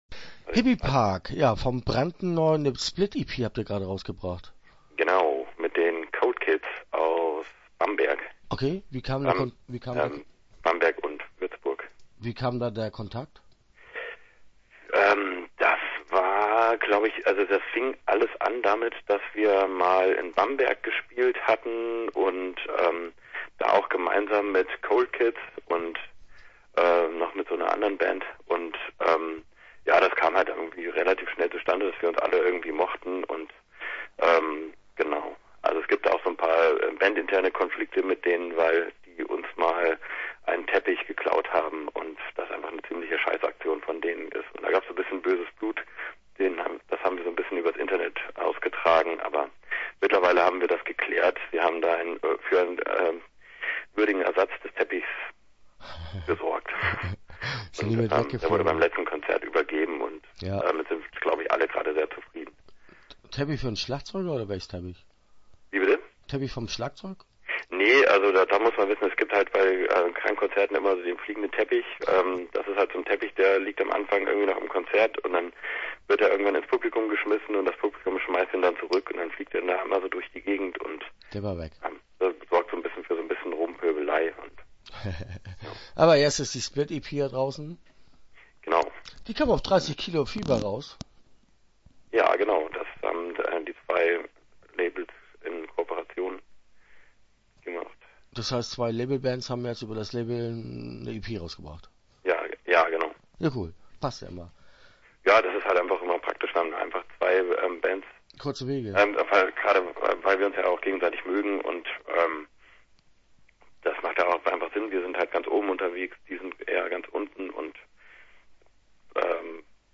Start » Interviews » Krank